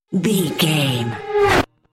Riser electronic fast
Sound Effects
In-crescendo
Atonal
Fast
driving
futuristic
intense
tension